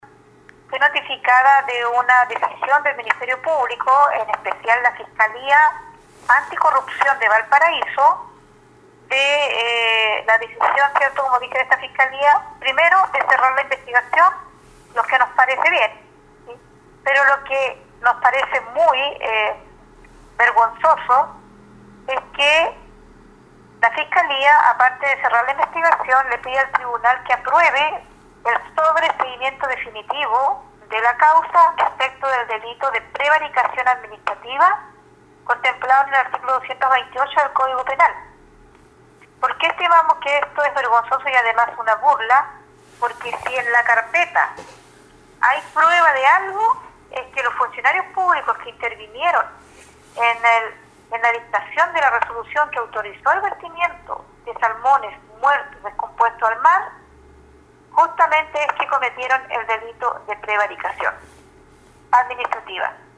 En entrevista con radio Estrella del Mar dijo que esta situación es grave dado que las acciones del ministerio público buscan dejar sin responsabilidades civiles ni políticas el alto daño ambiental y económico que generó el desastre ambiental en la región desde 2016.